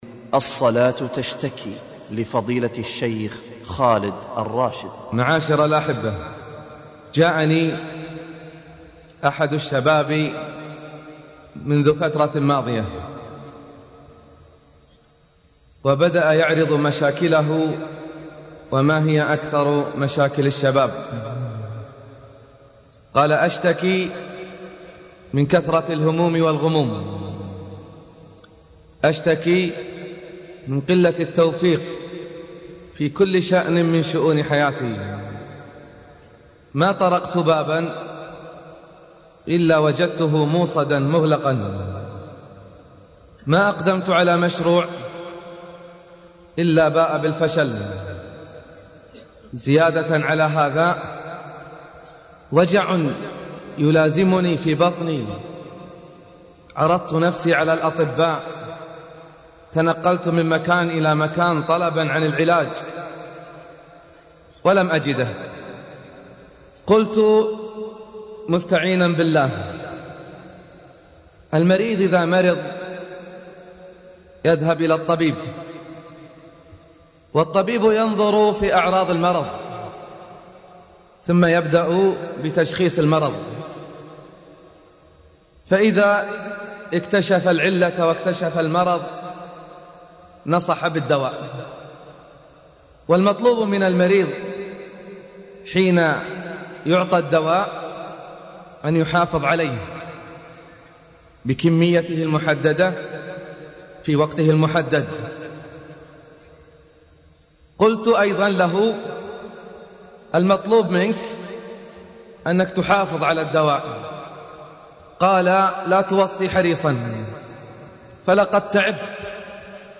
المحاضرات الصوتية